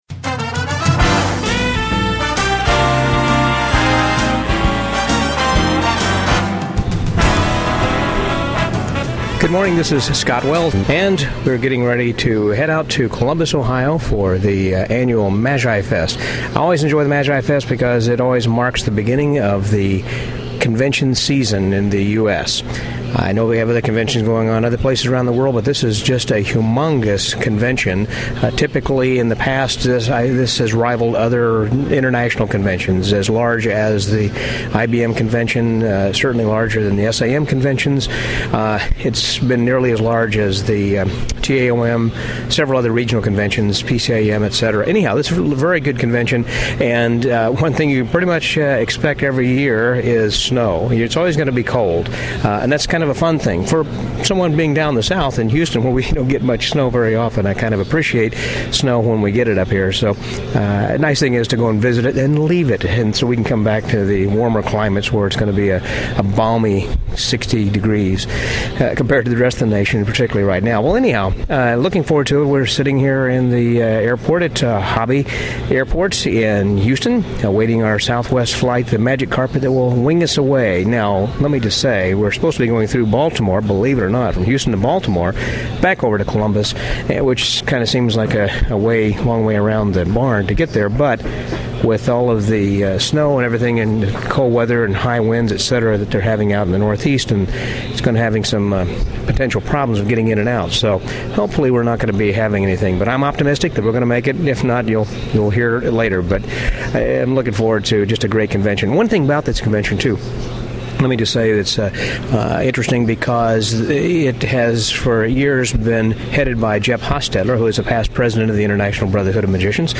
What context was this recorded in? This short podcast was recorded on the first day, January 27th, of the 2011 Magi-Fest in Columbus, Ohio